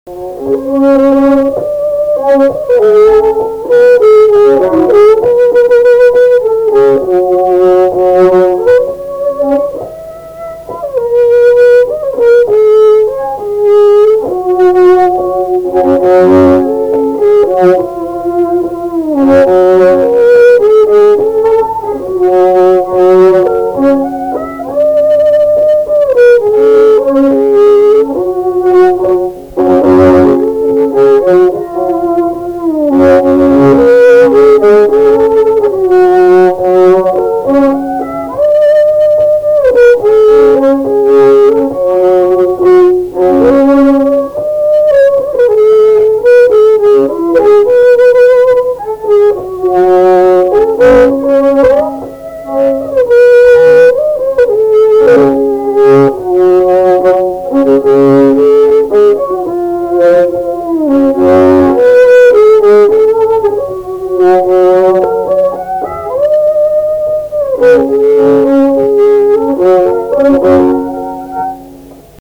Valsas
šokis